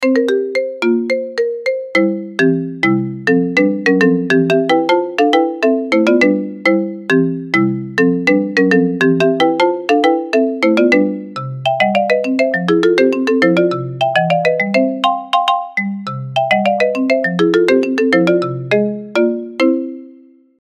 Genre: Nada dering viral TikTok